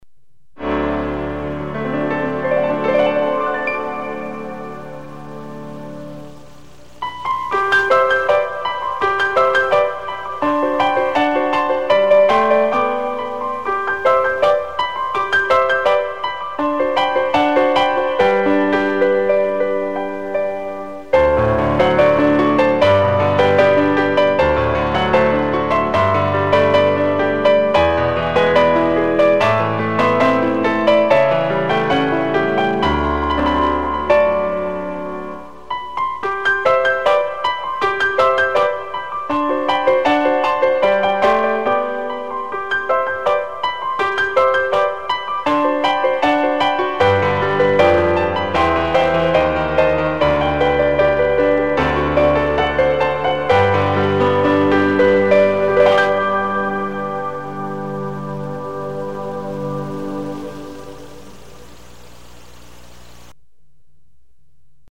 Ismeretlen Zongora részlet
zongora.mp3